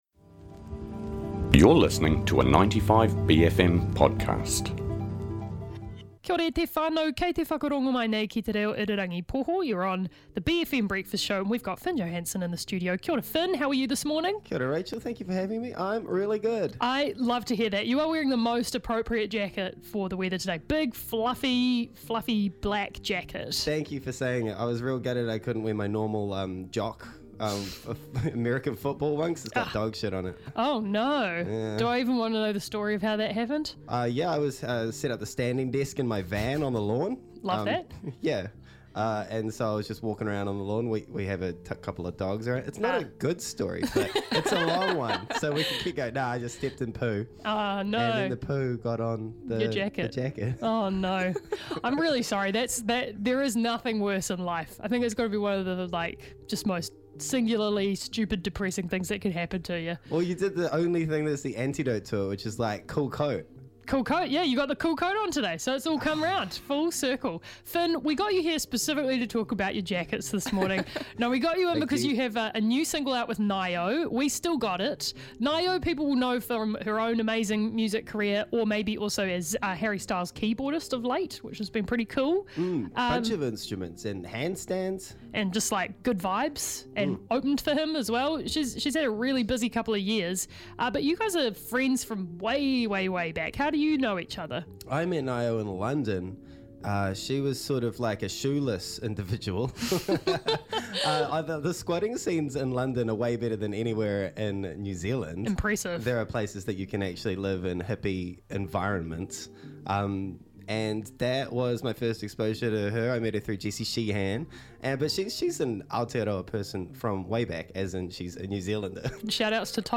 in the studio to chat